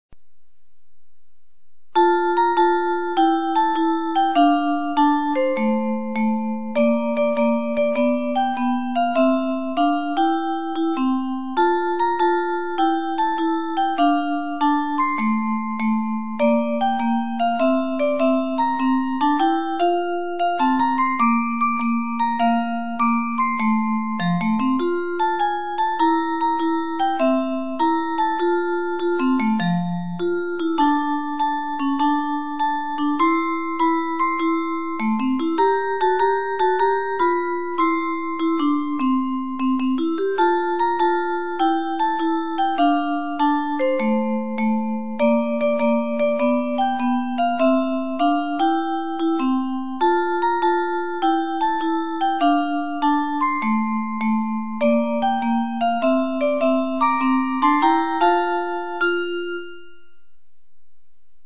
移動交番車用広報音楽